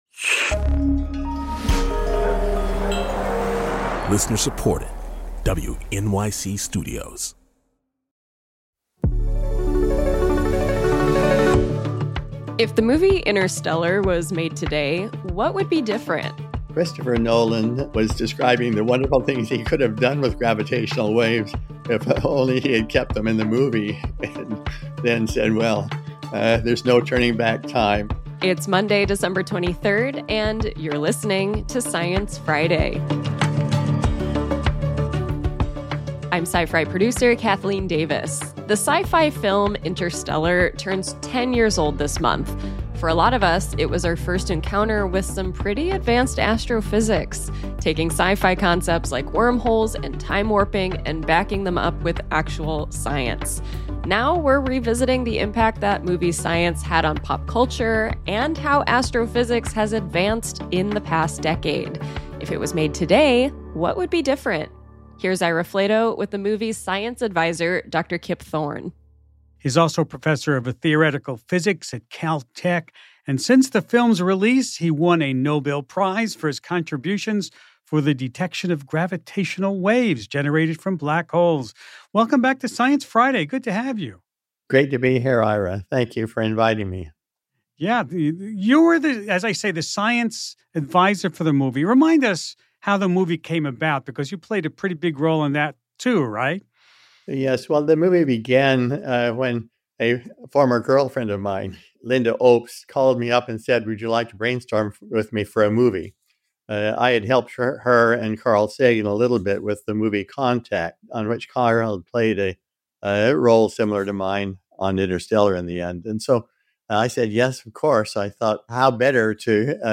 Ira Flatow sits down with “Interstellar” science advisor Dr. Kip Thorne, a professor of theoretical physics at the California Institute of Technology.